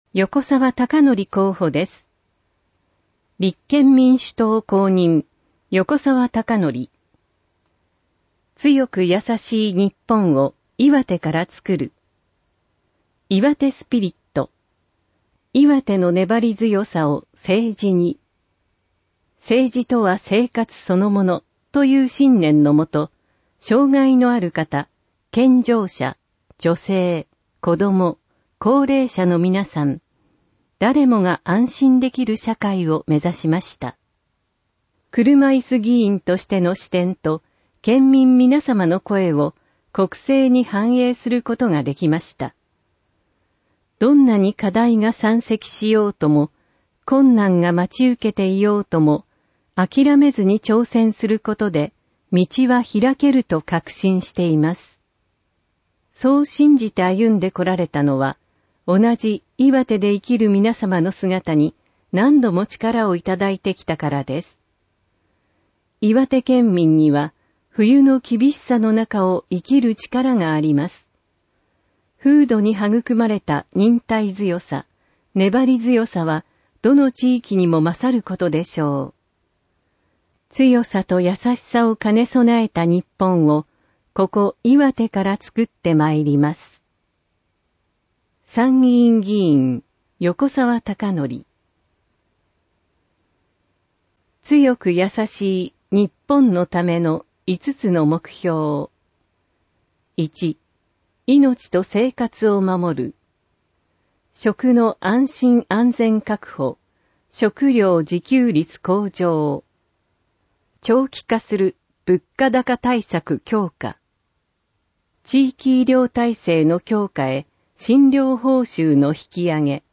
参議院議員通常選挙 候補者・名簿届出政党等情報（選挙公報） （音声読み上げ用）
選挙公報（全文音声版）